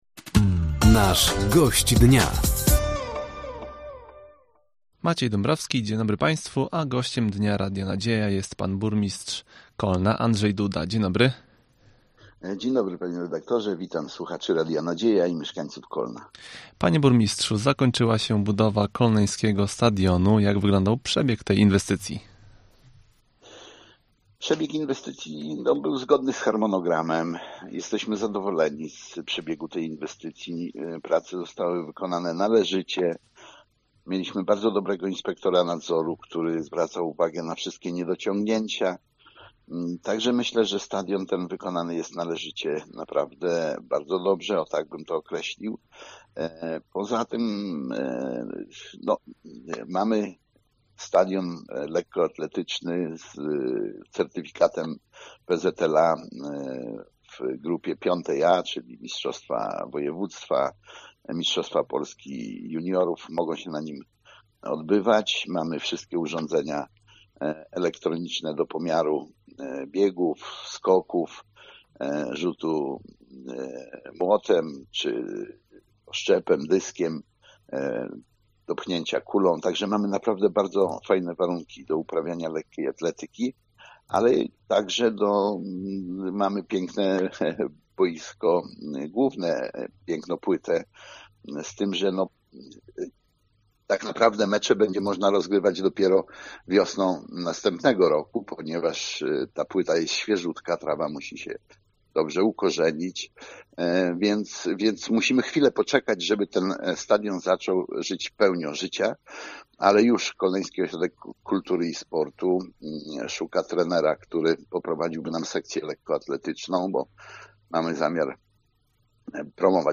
Gościem Dnia Radia Nadzieja był burmistrz Kolna, Andrzej Duda. Tematem rozmowy było zakończenie budowy stadionu miejskiego, inwestycja w infrastrukturę drogową i zbliżająca się sesja rady miasta.